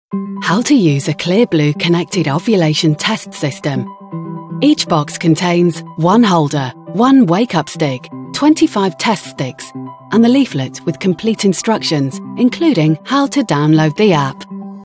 Anglais (Britannique)
Distinctive, Polyvalente, Amicale